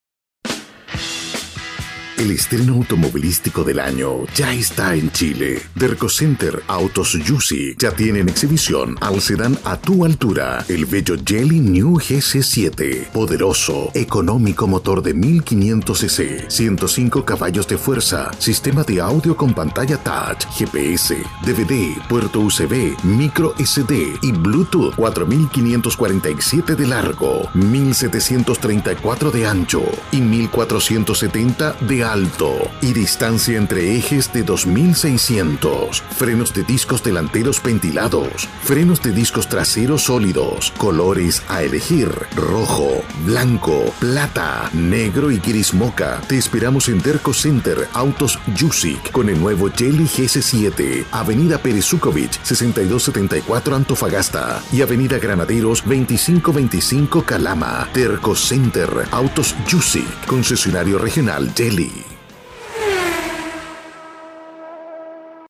LOCUTOR PROFESIONAL MAS DE 25 AÑOS DE EXPERIENCIA EN ESTACIONES DE RADIO Y AGENCIAS, ADEMAS ACTOR DOBLAJISTA.(VOICEOVER)
spanisch Südamerika
chilenisch
Sprechprobe: Industrie (Muttersprache):